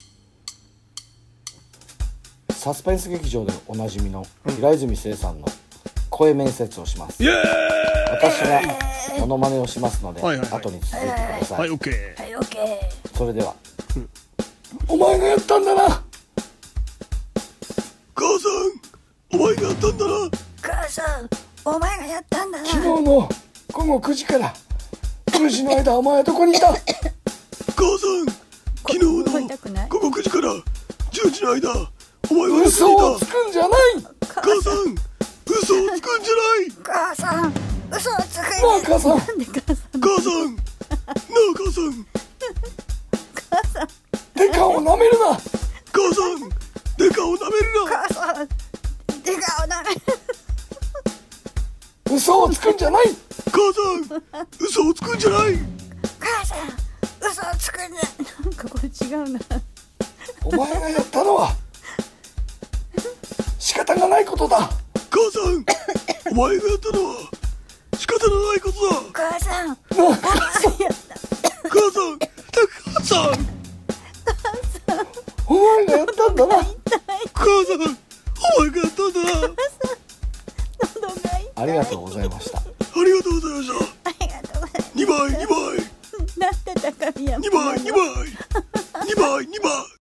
声面接 平泉成モノマネ【刑事シリーズ】